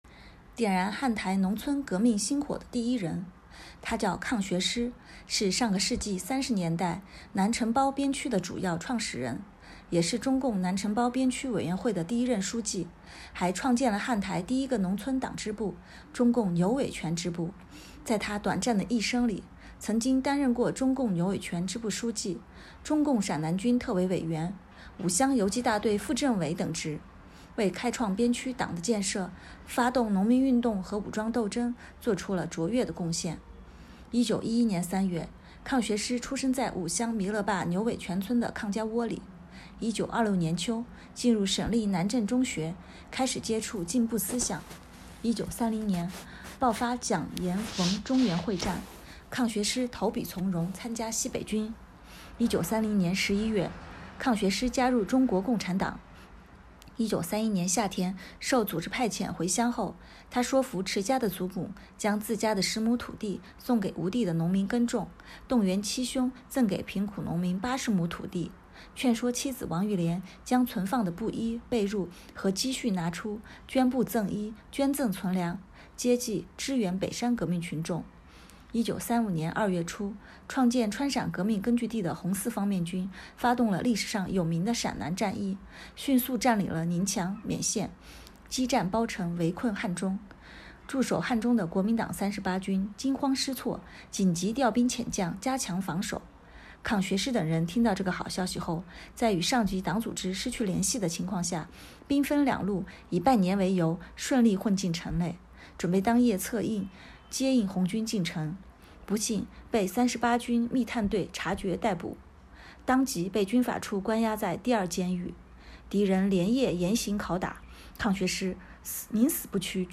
【红色档案诵读展播】点燃汉台农村革命星火的第一人